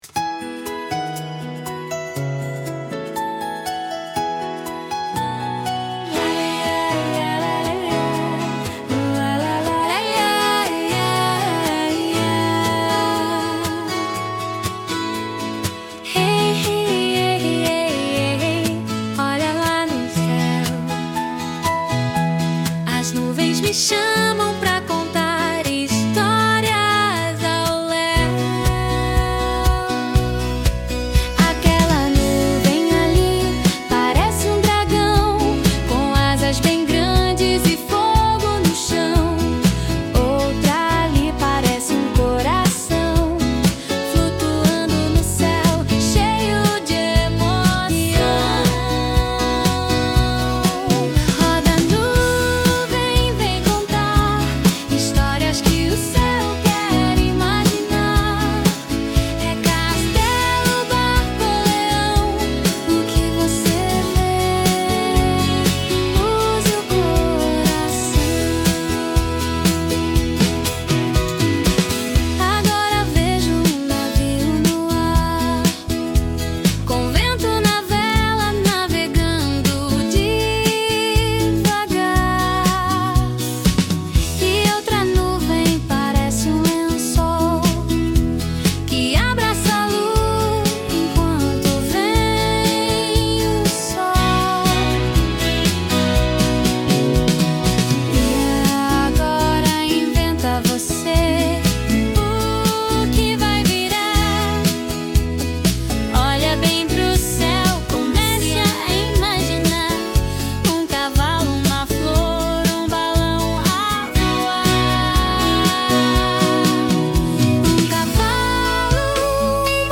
EstiloNew Age